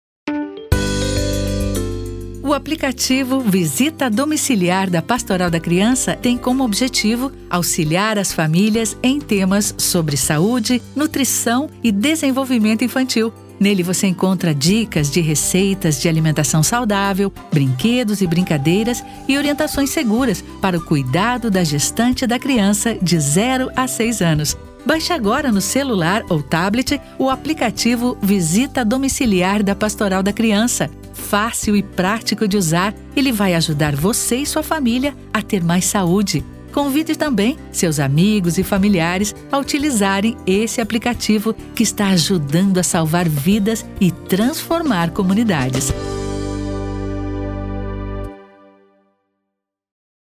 Spot_Aplicativo_da_Pastoral_voz_feminina.mp3